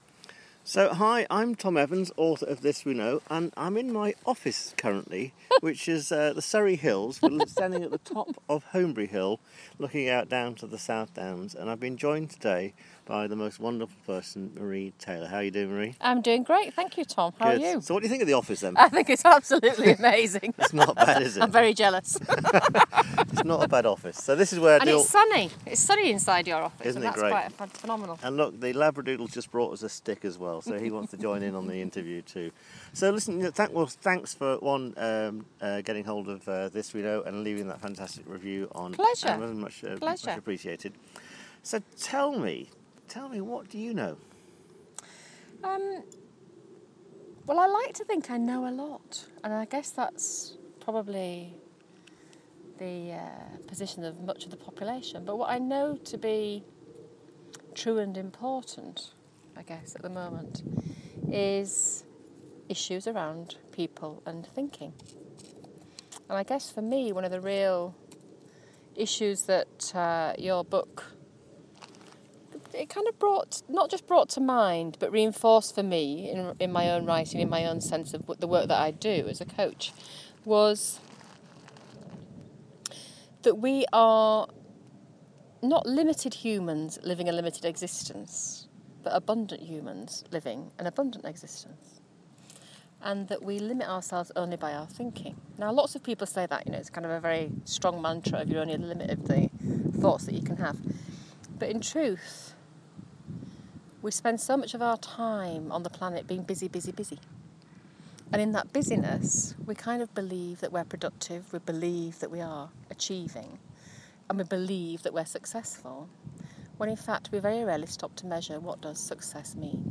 Lovely chat
on Holmbury Hill, Surrey